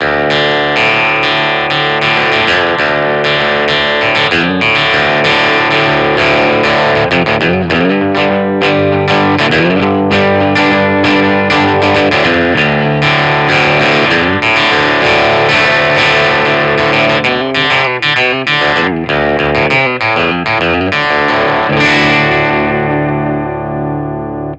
Электрогитара FENDER SQUIER AFFINITY TELECASTER MN BLACK